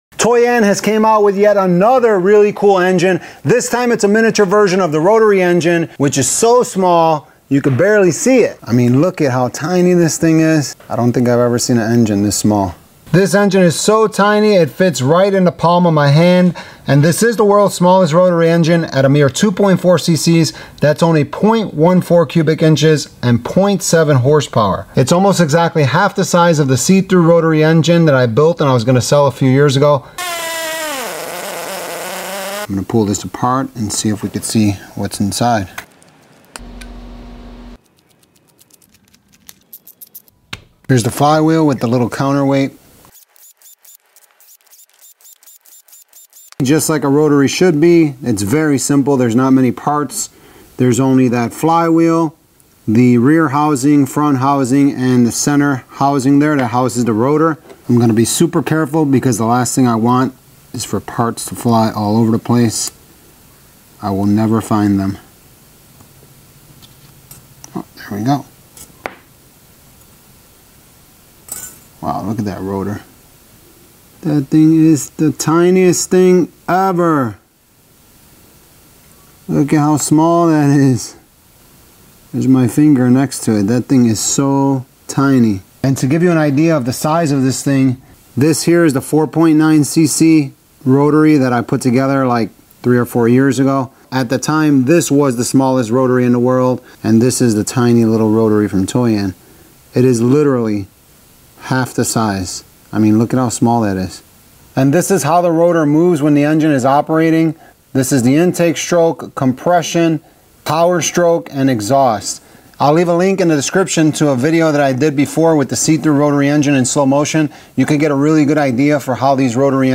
Worlds Smallest Rotary Engine (30.000 RPM)